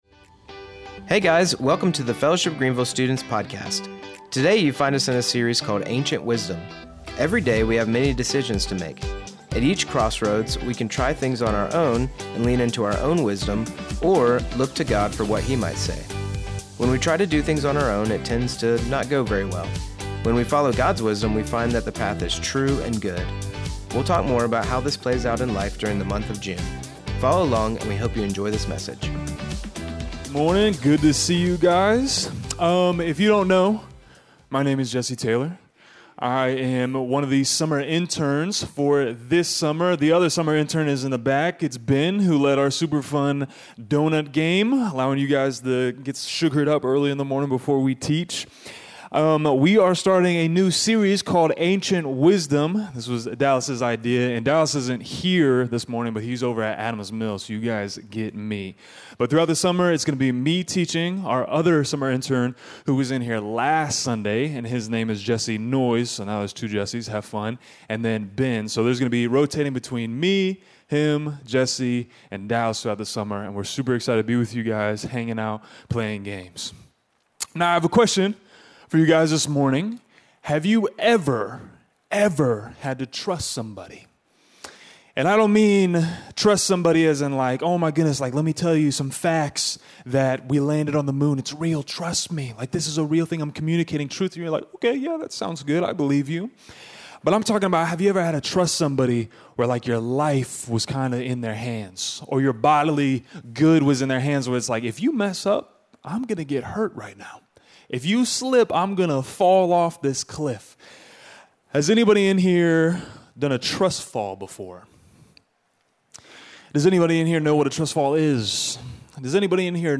Follow along and we hope you enjoy this message.